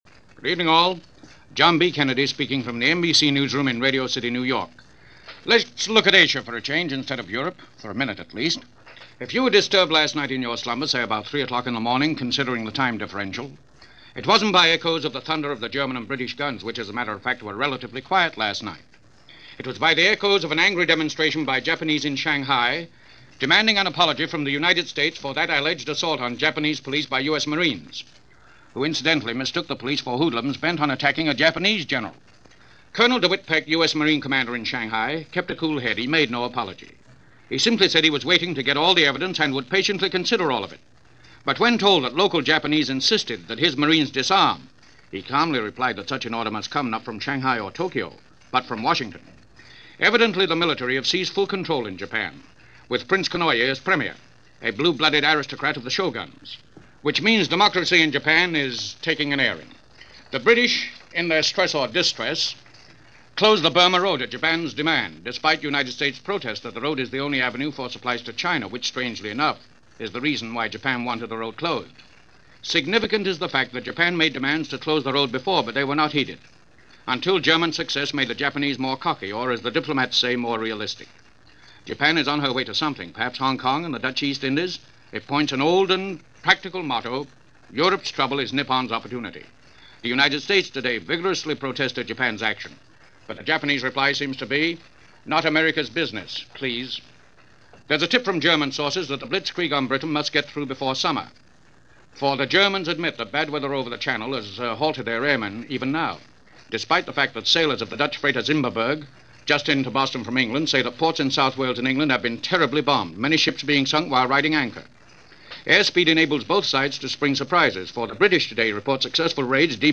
War: The Ominous Shade Of Summer - July 17, 1940 - news reports from overseas - NBC Red Network News Of The World